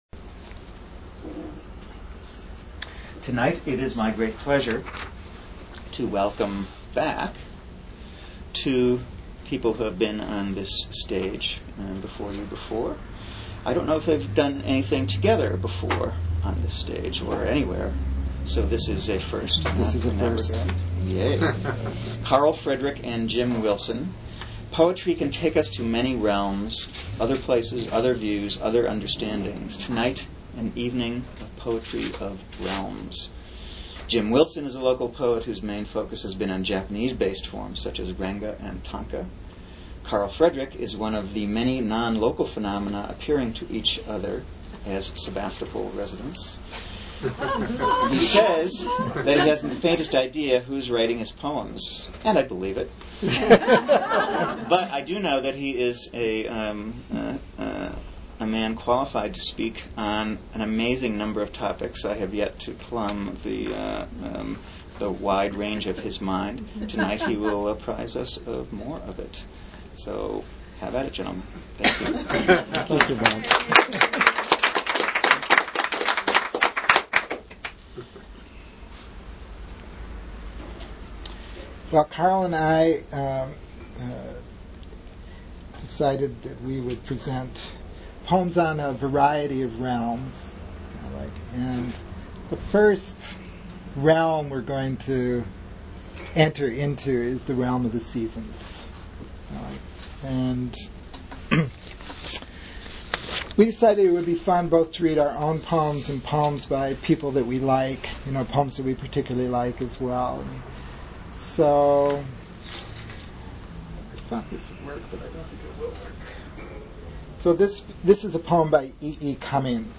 Archive of an event at Sonoma County's largest spiritual bookstore and premium loose leaf tea shop.